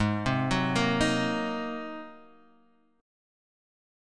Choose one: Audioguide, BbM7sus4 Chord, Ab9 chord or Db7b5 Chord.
Ab9 chord